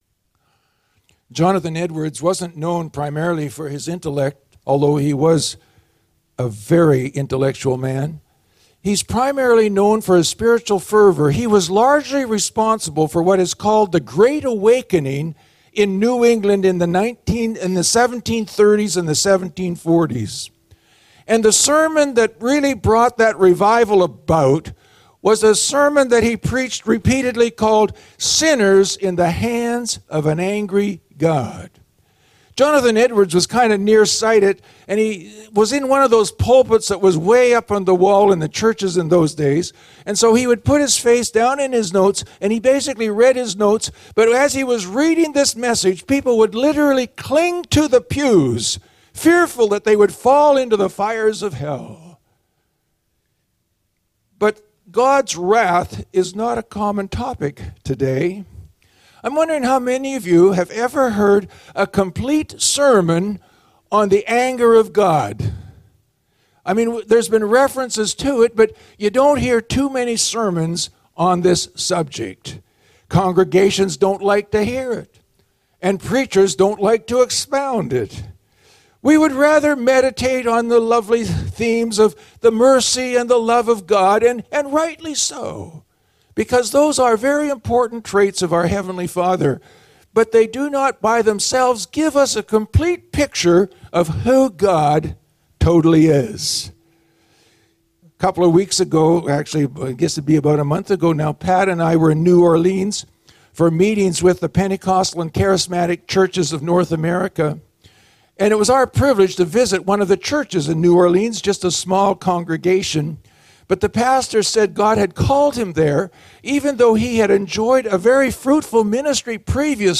Sermons | Surrey Pentecostal Assembly